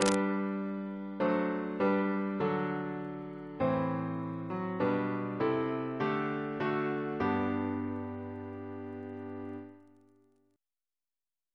CCP: Chant sampler
Single chant in G Composer: John Alcock (1715-1806), Organist of Lichfield Cathedral Reference psalters: ACB: 57; OCB: 119; PP/SNCB: 193